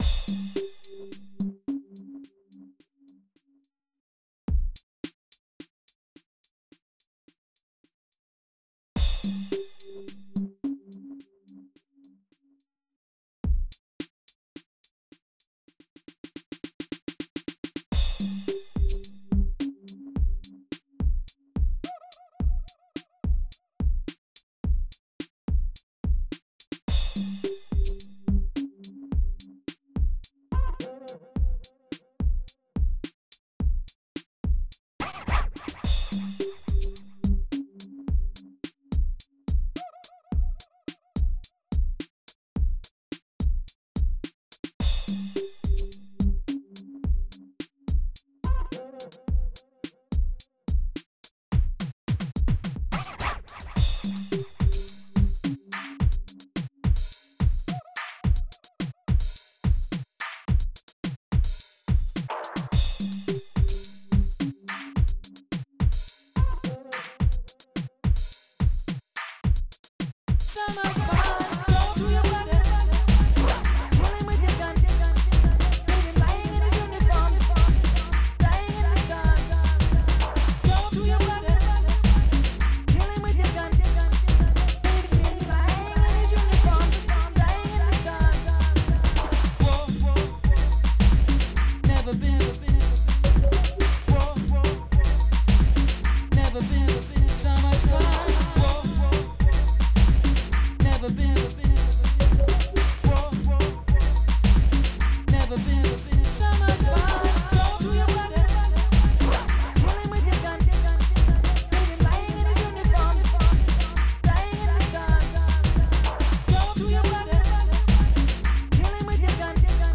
xm (FastTracker 2 v1.04)
(funky break mix)